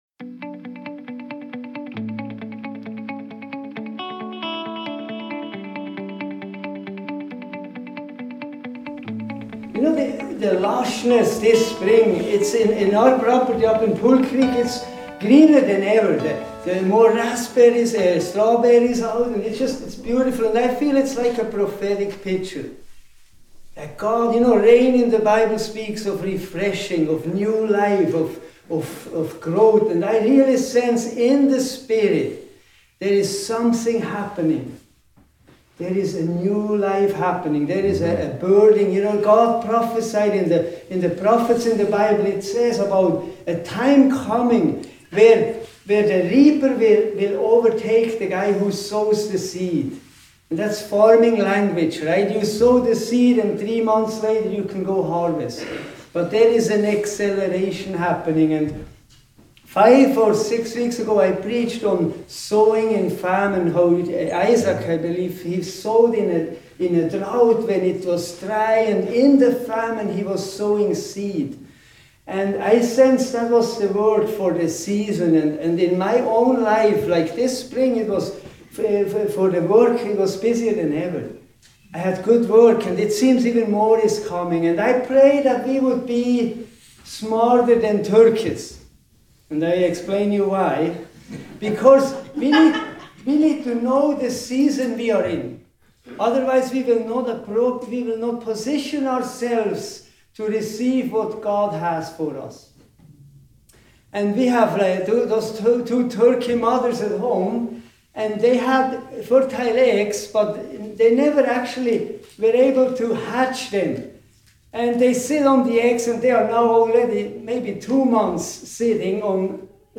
Sermons | Pemberton Community Church